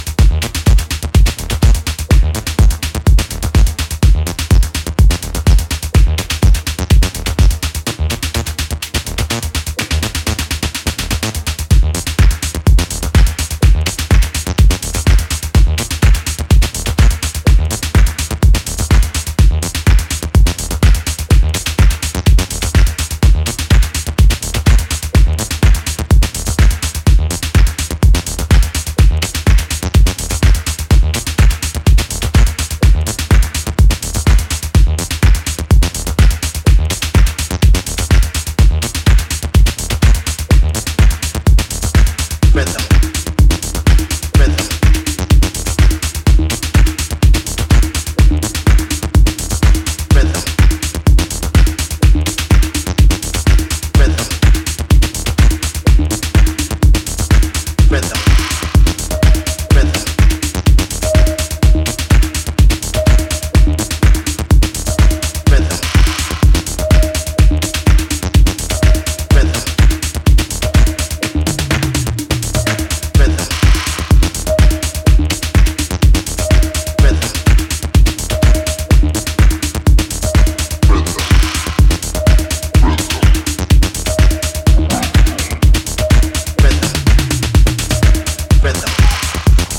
ミニマルなアシッドリフがじわじわと深夜帯のフロアを侵食する